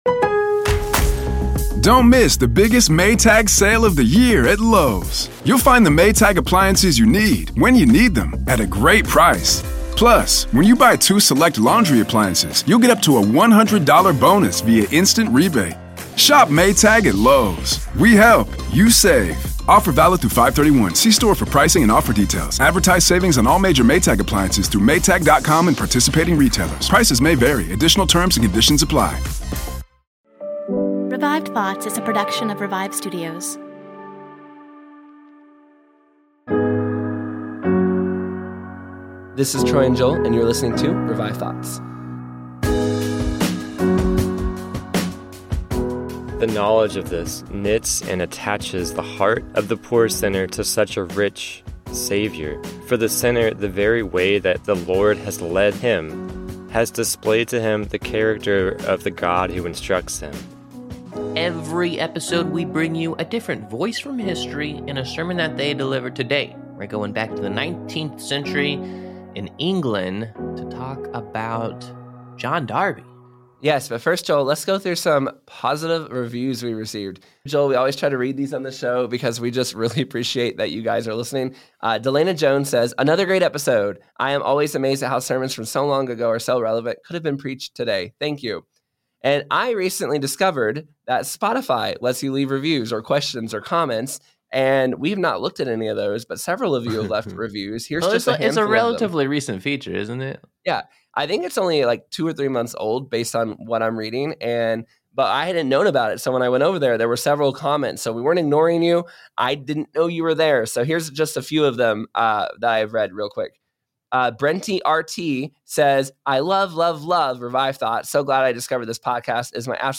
Hear his story and his sermon, "The Wheat and The Tares."